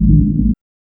2608R BASS.wav